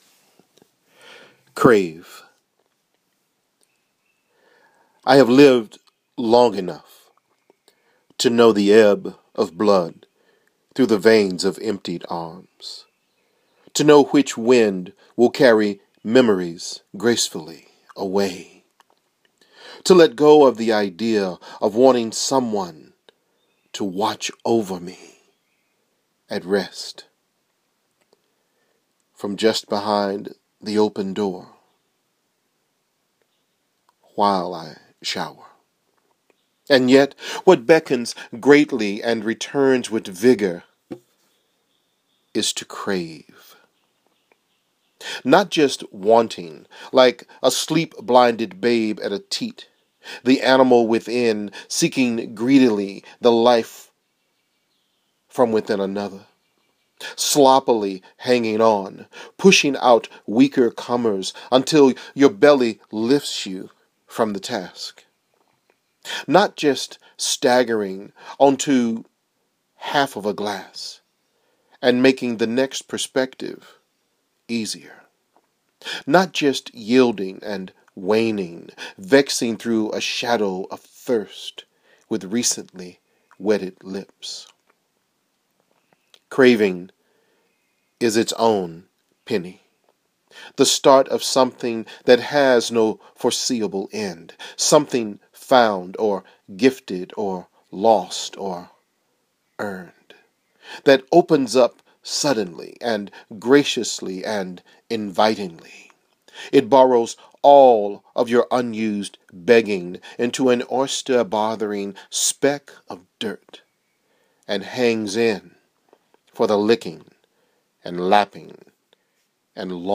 CRAVE (a read poem)